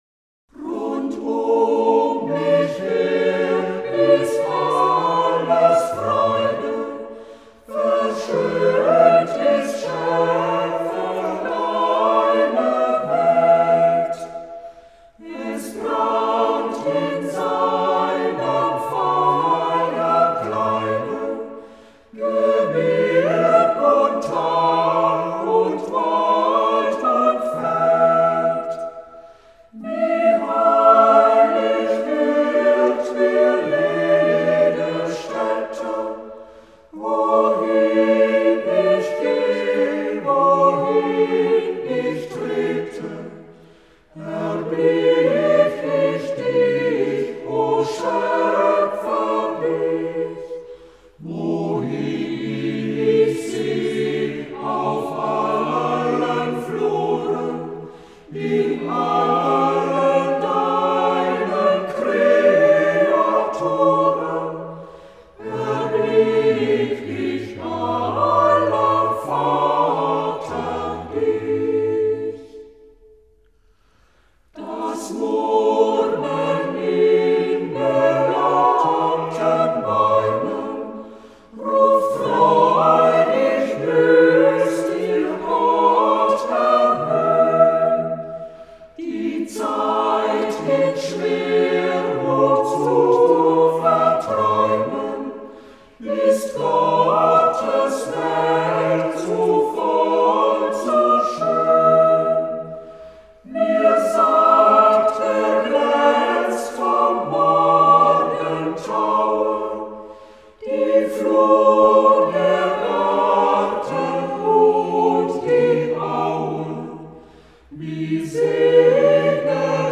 Gesungene Version des Biberacher Kammerchores